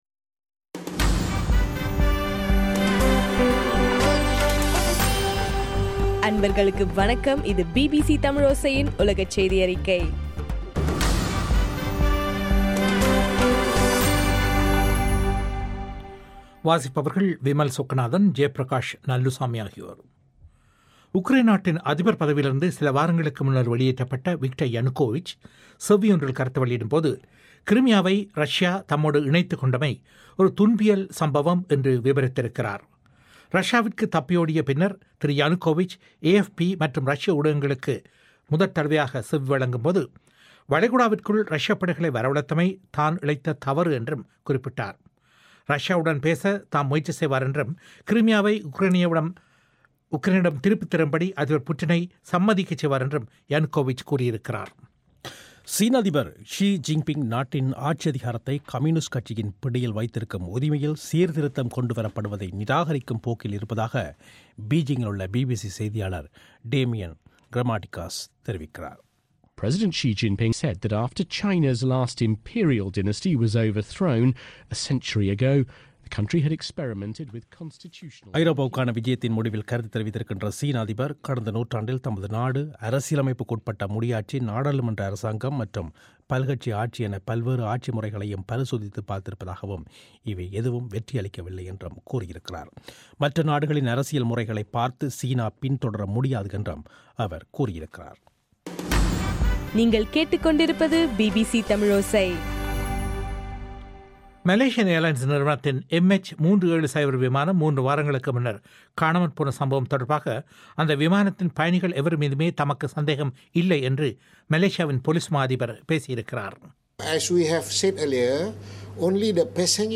இலங்கையின் சக்தி எப்எம் வானொலியில் ஒலிபரப்பான பிபிசி தமிழோசையின் உலகச் செய்தியறிக்கை